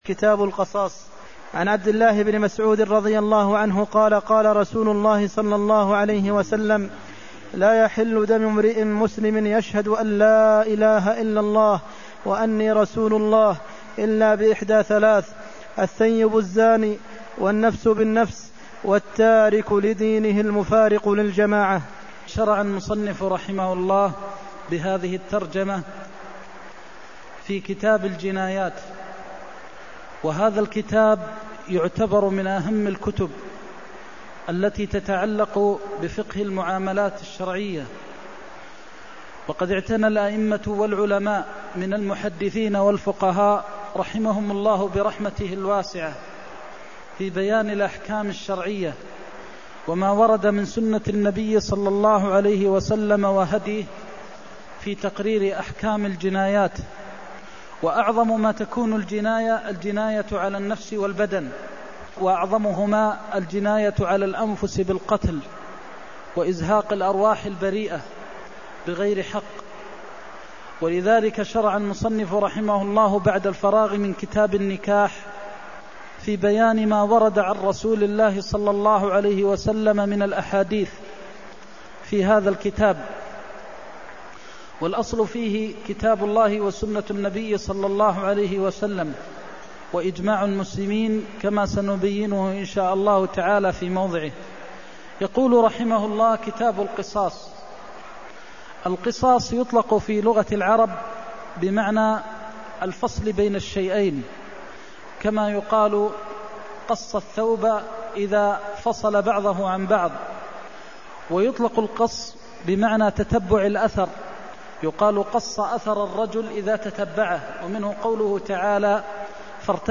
المكان: المسجد النبوي الشيخ: فضيلة الشيخ د. محمد بن محمد المختار فضيلة الشيخ د. محمد بن محمد المختار لا يحل دم امرىء مسلم إلا بإحدى ثلاث (319)